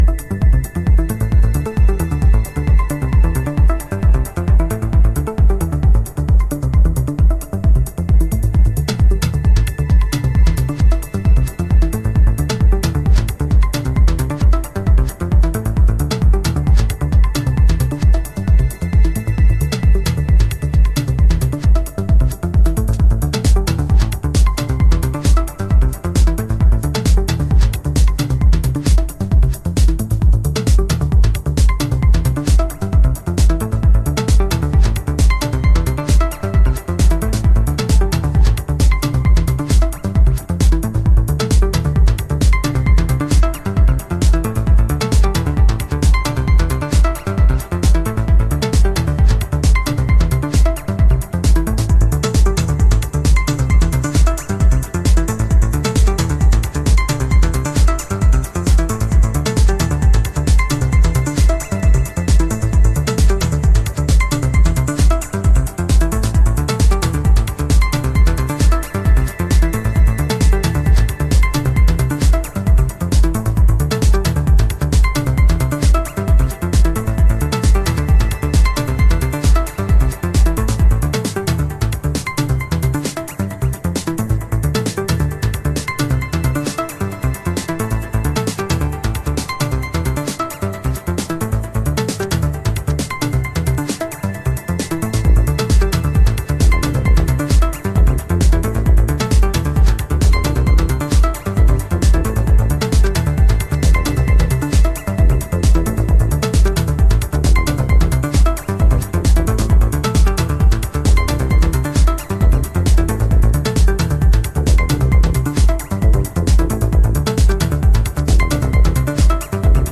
House / Techno
このメロデーとマシーンドラムが癖になるんですよね。